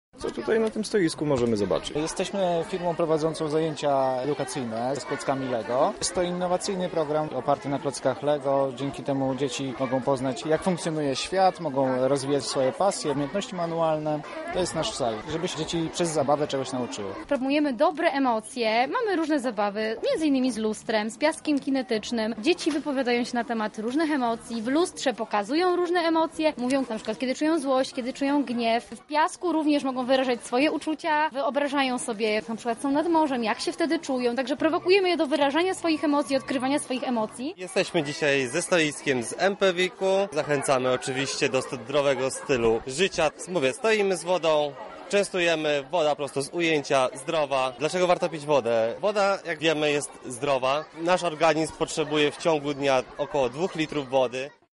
O tym przekonał się nasz reporter.